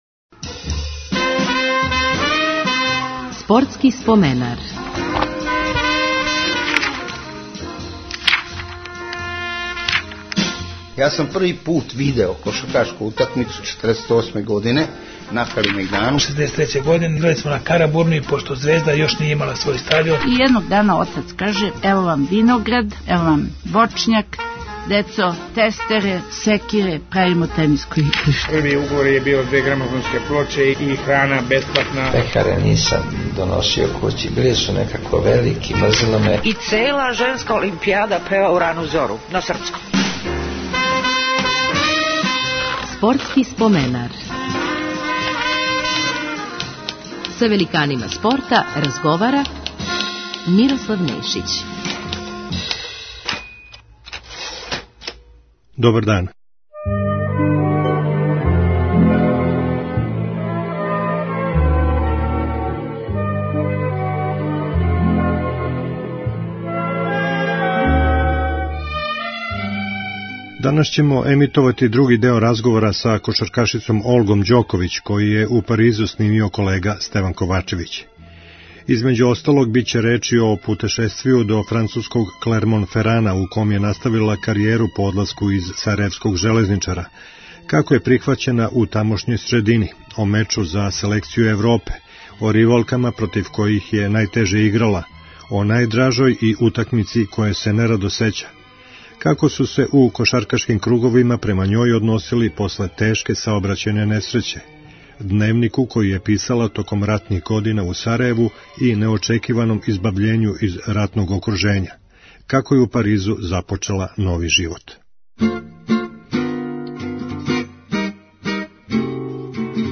Други део разговора са кошаркашицом